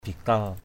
/bi-kal/ 1.
bikal.mp3